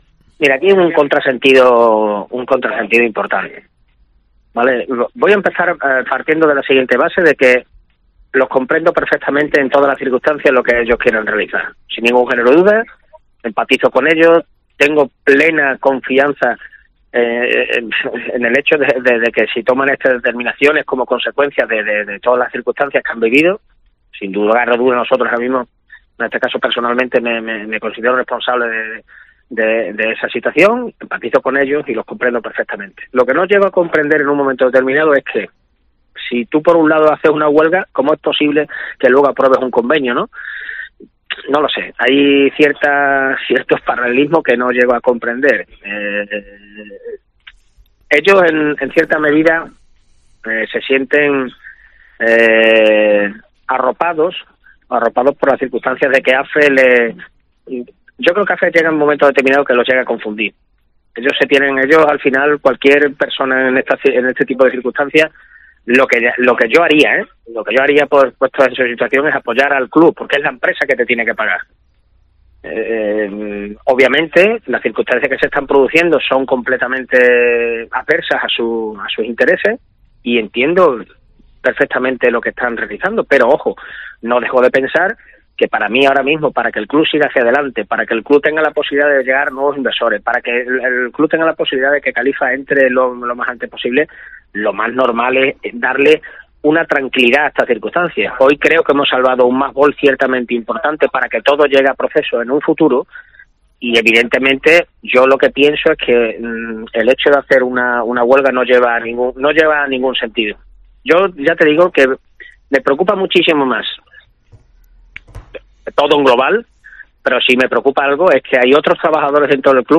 en declaraciones a COPE Almendralejo